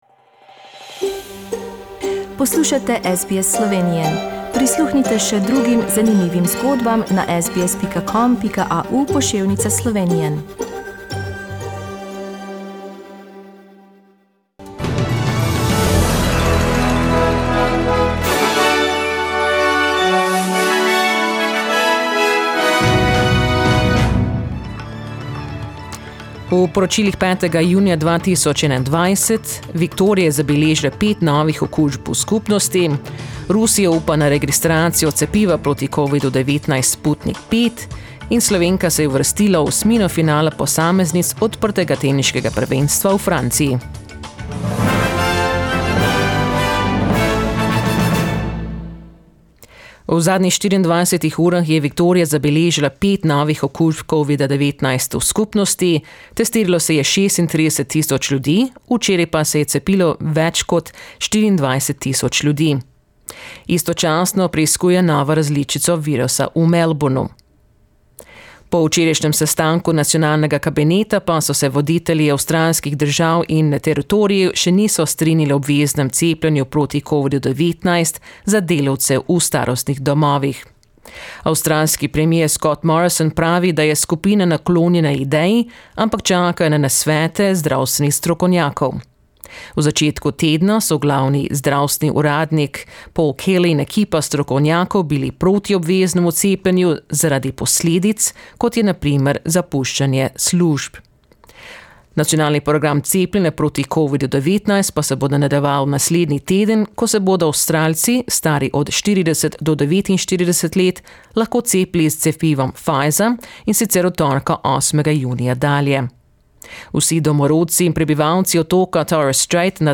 Listen to the latest news headlines in Australia from SBS Slovenian radio.